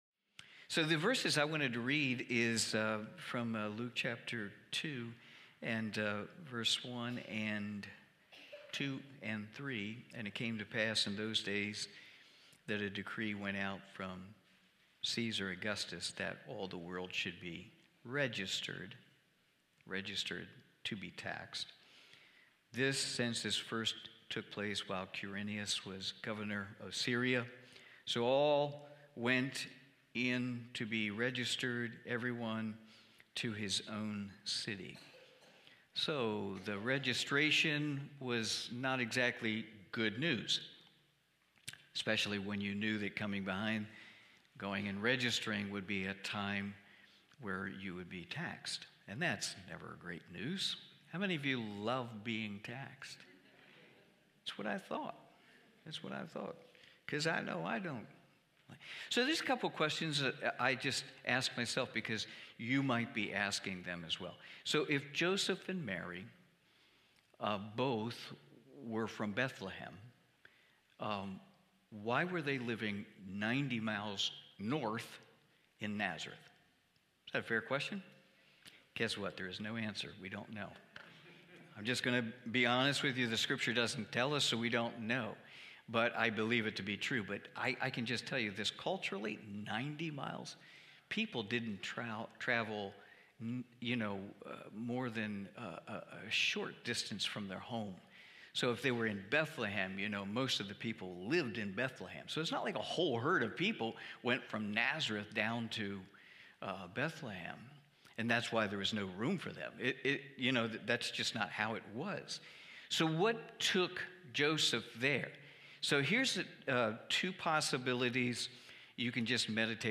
Cornerstone Fellowship Christmas Eve service, livestreamed from Wormleysburg, PA.